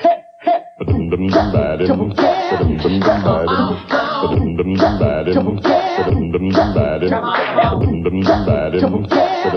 Opening music loop hiss has been cleaned up.